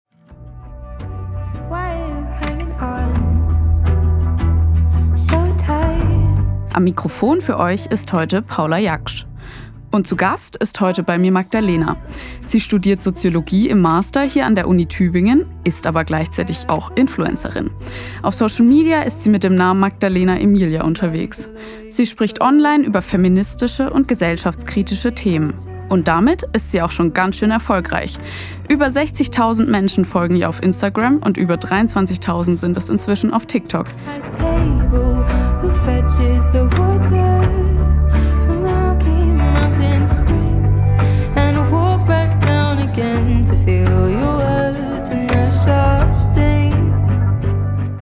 Teaser_583.mp3